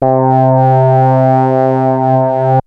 OSCAR PAD 01 3.wav